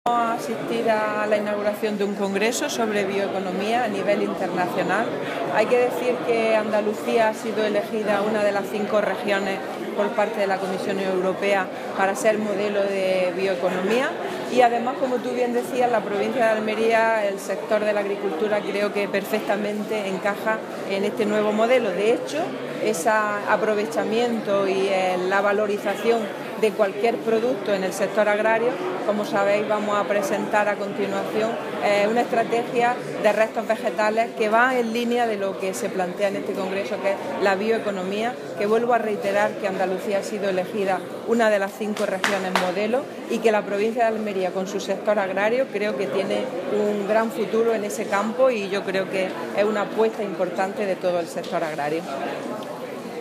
Declaraciones de la consejera sobre eI Congreso Nacional de Bioeconomía, Alimentación y Futuro